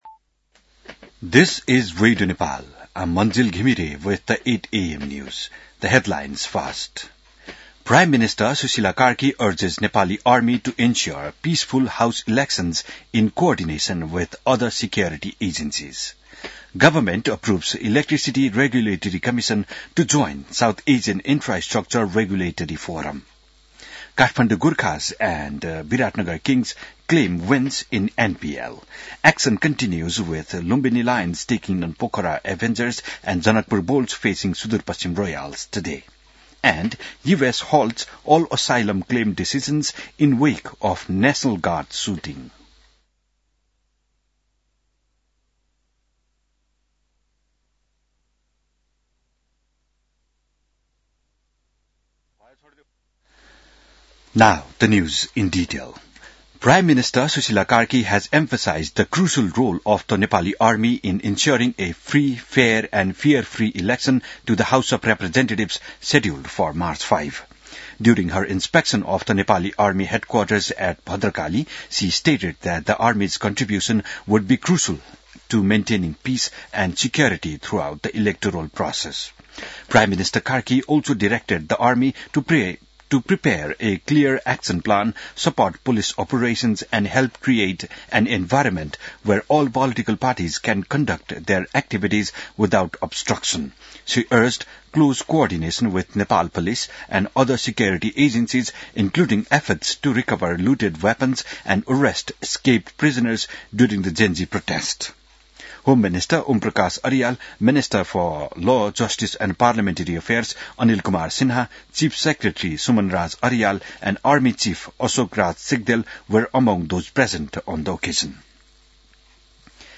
बिहान ८ बजेको अङ्ग्रेजी समाचार : १३ मंसिर , २०८२